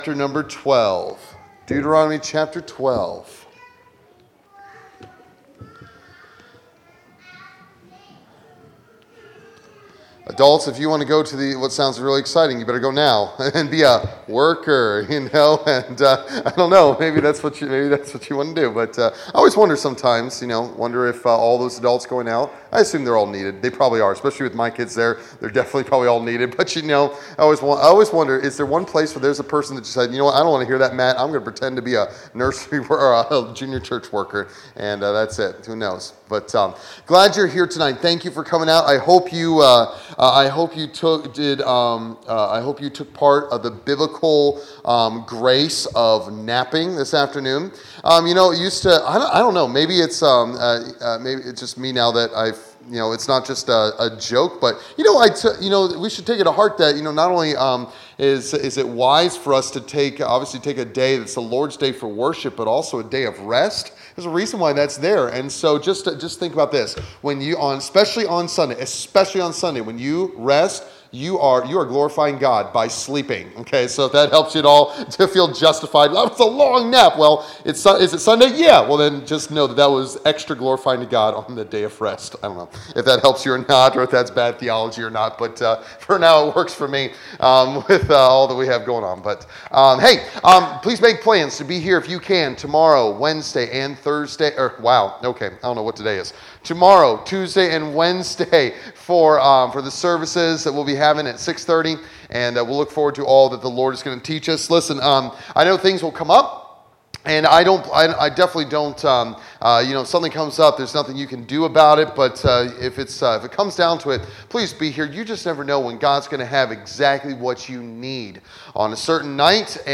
Series: Revival Meeting Passage: Deuteronomy 12